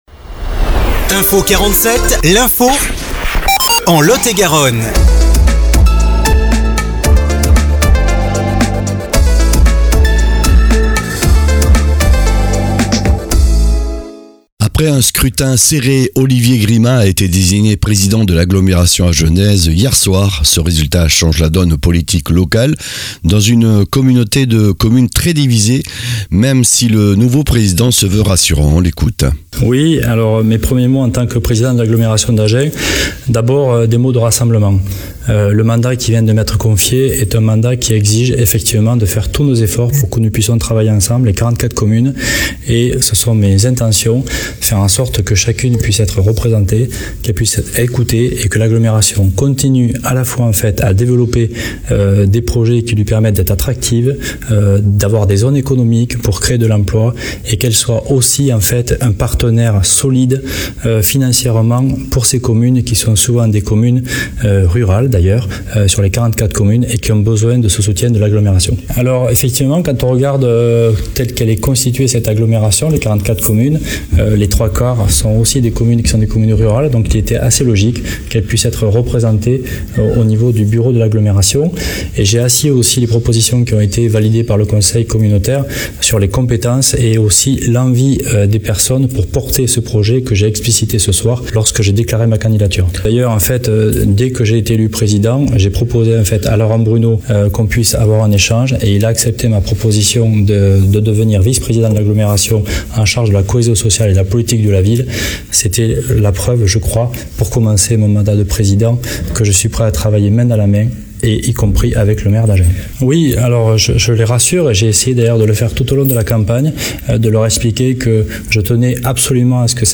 Flash infos 10/04/2026